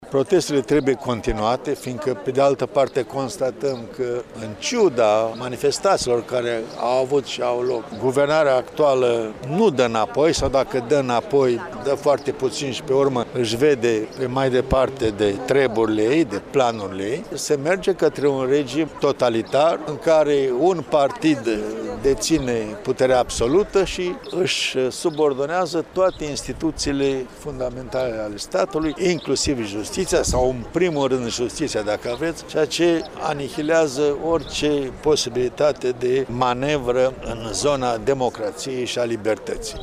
prezent astăzi la protest